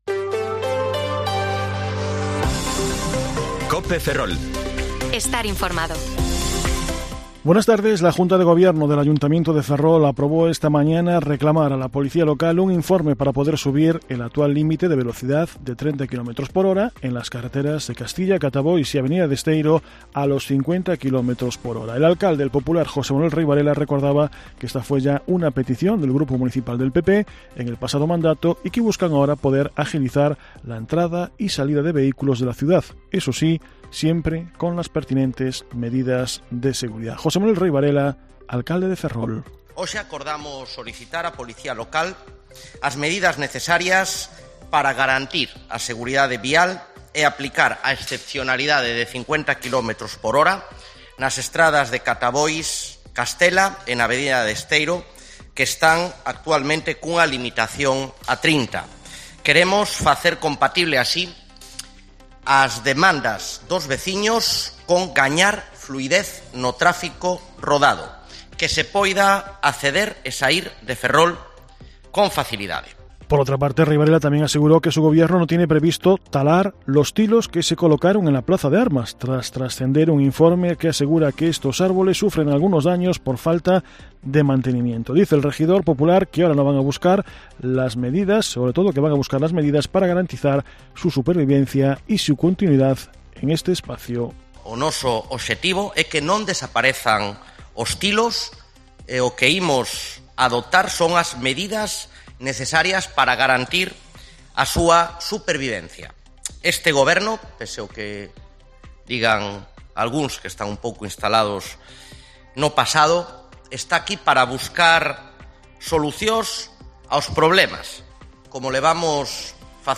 Informativo Mediodía COPE Ferrol 28/08/2023 (De 14,20 a 14,30 horas)